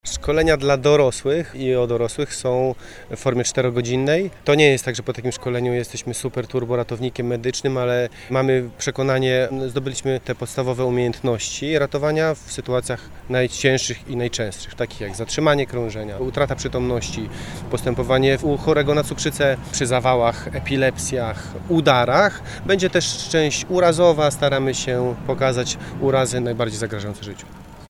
W centrum Wrocławia (pl. Nowy Targ) zorganizowano plenerowe szkolenie z zakresu udzielania pierwszej pomocy medycznej.
02_ratownik-1.mp3